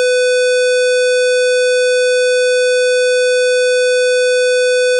2 0-term Fourier series representation of 500 Hz even squarewave Even squarewave sound file 20-term Fourier series representation of 500 Hz even squarewave -- reconstructed using sine instead of cosine Sine-reconstruction audio file